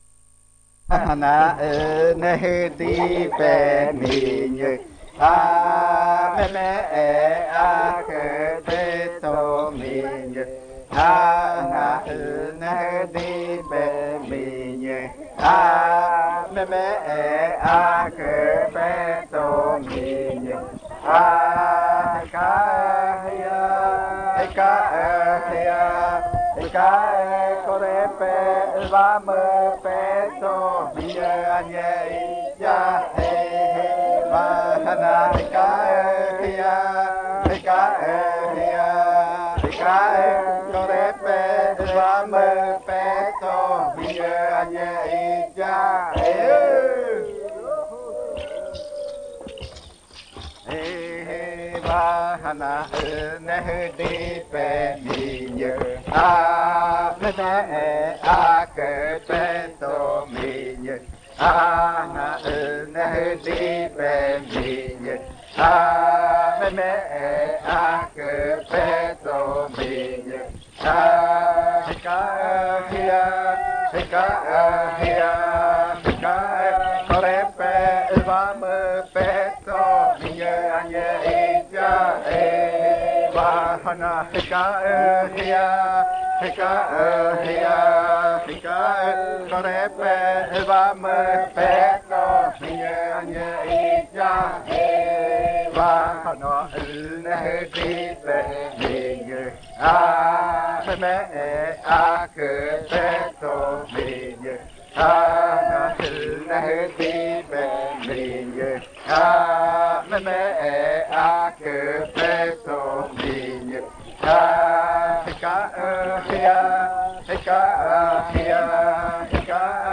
34. Baile de nombramiento. Canto n°14
Puerto Remanso del Tigre, departamento de Amazonas, Colombia
Canto número catorce del baile de Pichojpa Majtsi (de nombramiento) del pueblo miraña
en casete y en el año de 1990
Se escuchan las voces principales